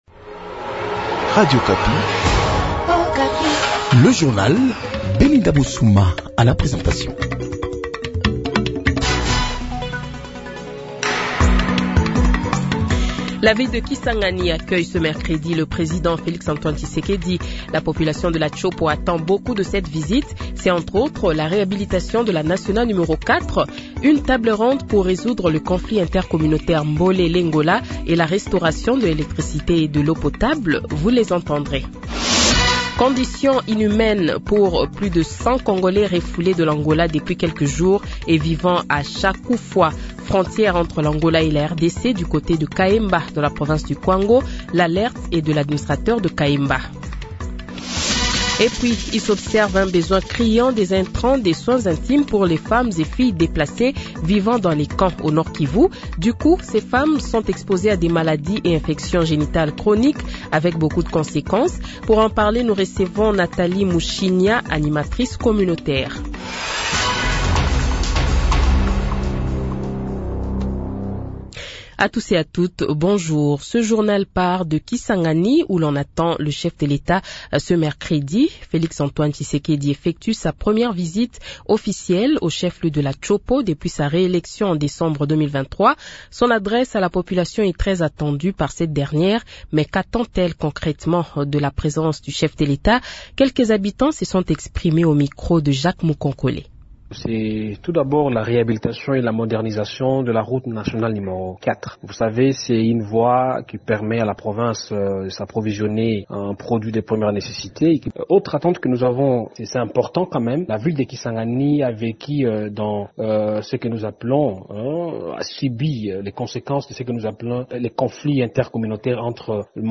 Le Journal de 8h, 23 Octobre 2024 :